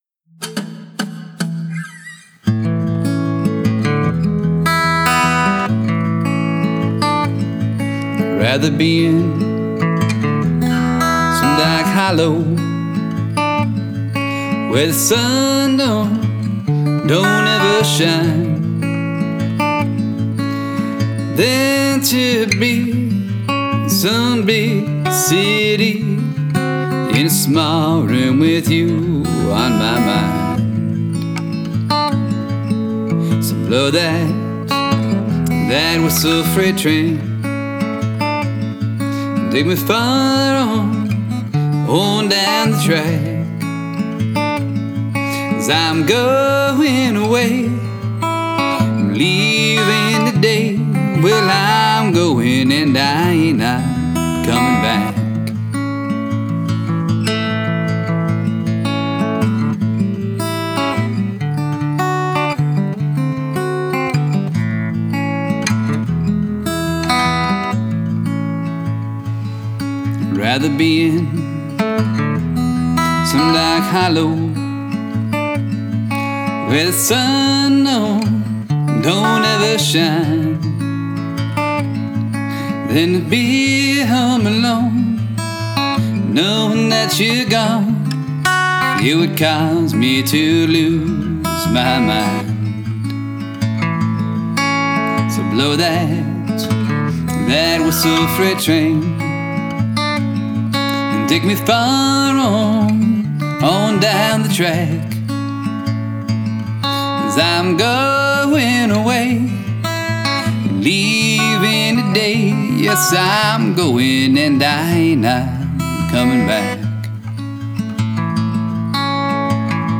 I do owe the Dead one very proper debt of gratitude: They introduced me to a slew of old-timey tunes, and made me believe I could play along too.
I’ve been picking at this one off and on for years, since that time when we were the new kids, trying things on, doing our best to rattle the comfy collegiate cage so graciously provided.